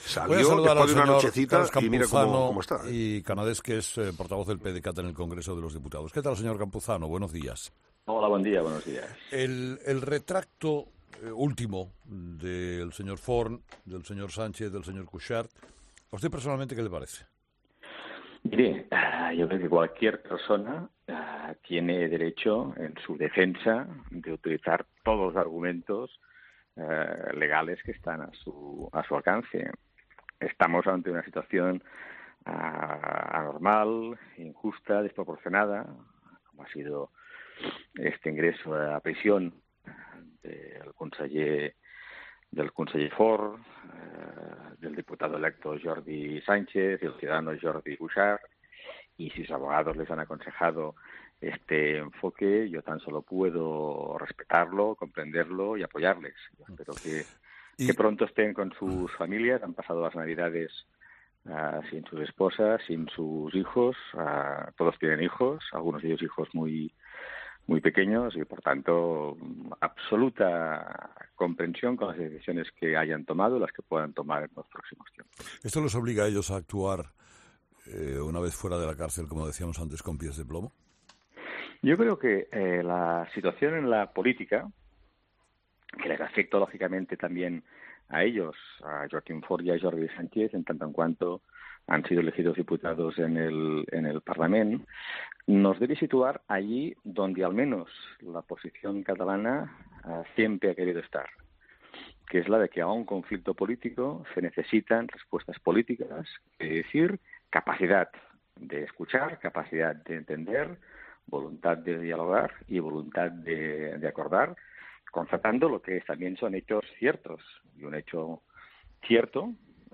Carles Campuzano, portavoz del PDeCAT en el Congreso, no cree que se esté produciendo una desbandada en las filas separatistas después de que Forn, Sánchez y Cuixart admitieran este jueves en el Supremo que se cometieron ilegalidades y que sólo es válida la vía constitucional para conseguir la independencia.
Si sus abogados le han aconsejado ese enfoque, lo respeto y lo apoyo" En una entrevista en ' Herrera en COPE ', Campuzano cree que todos los líderes del 'procés' han asumido riesgos y entiende que muchos no quieran seguir al frente, haciendo una clara referencia a Forcadell, que ayer dijo que no sería presidenta del Parlament; y Carles Mundó, que el miércoles rechazó su acta de diputado.